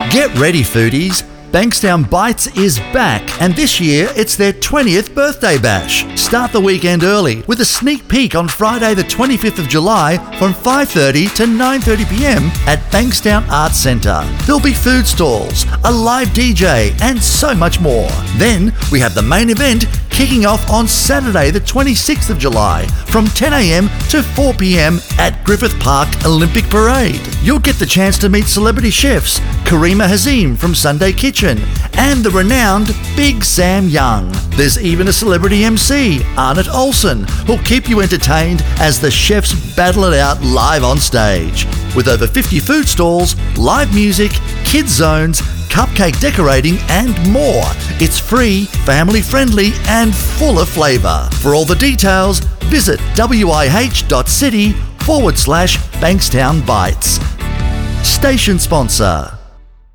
Adult (30-50)